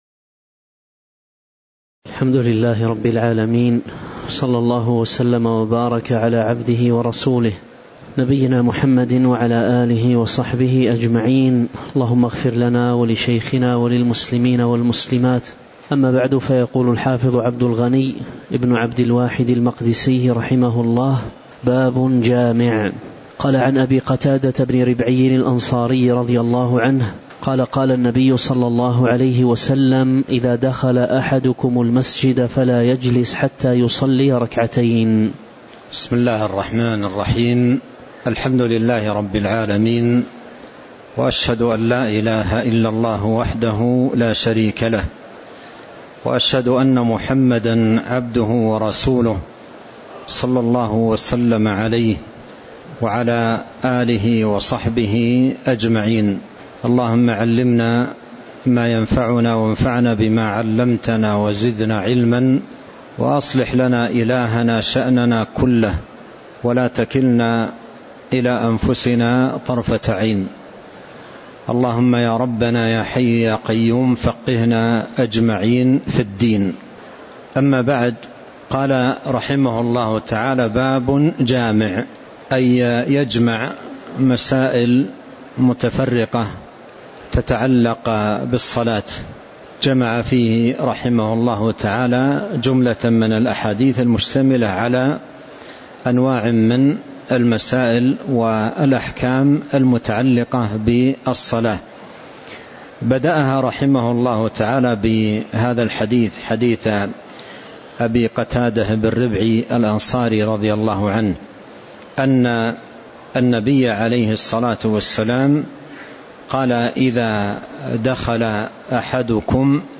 تاريخ النشر ١٣ ربيع الثاني ١٤٤٤ هـ المكان: المسجد النبوي الشيخ